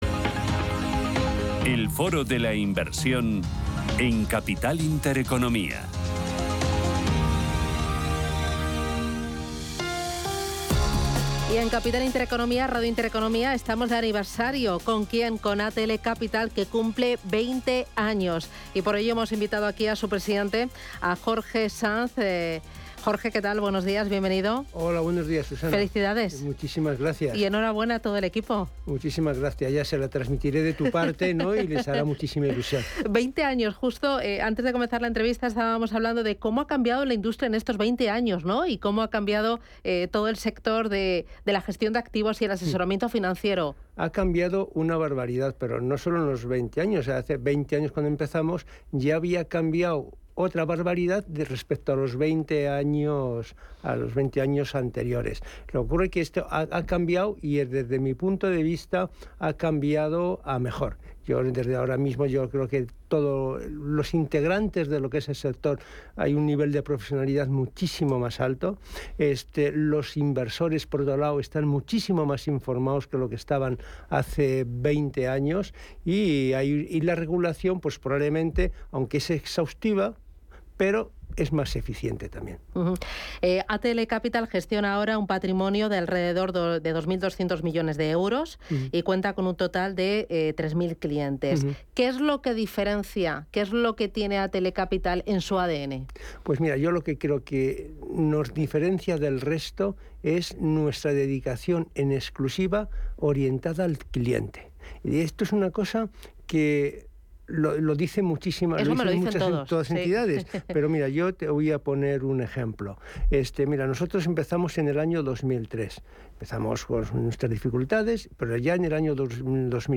Entrevista
Aquí el audio de la entrevista completa